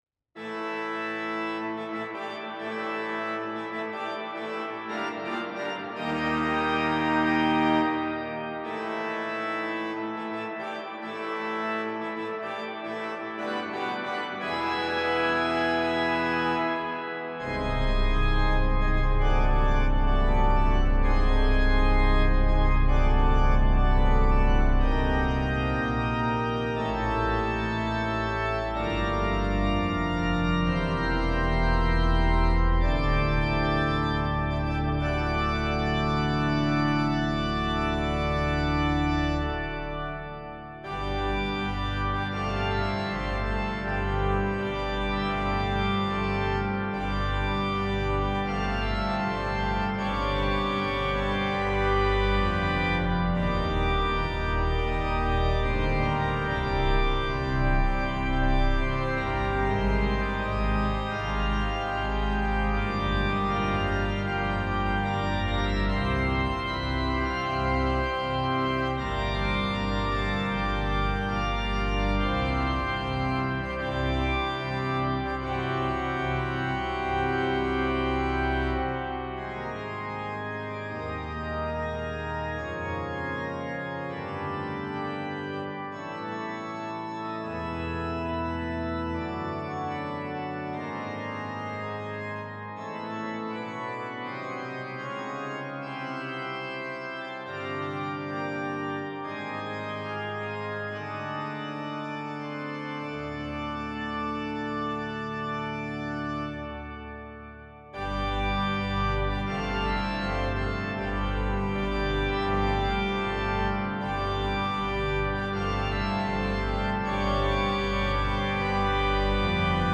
The piece is music of festive processional character.